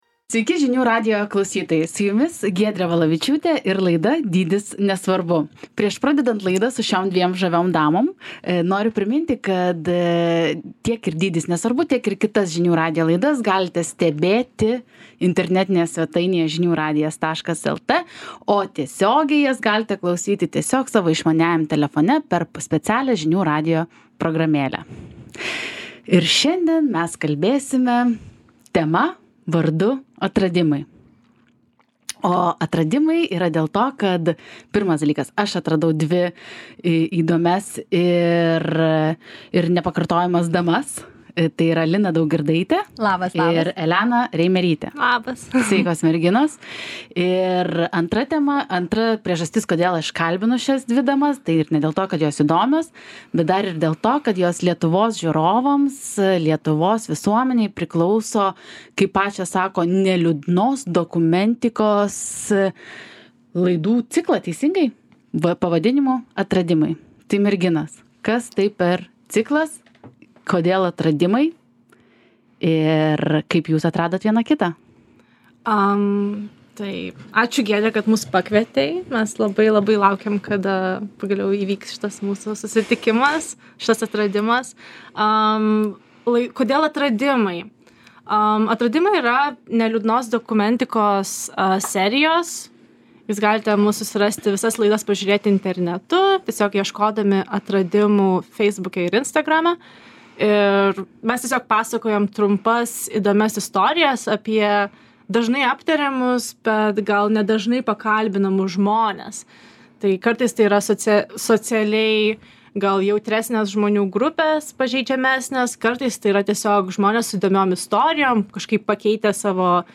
Laidos viešnios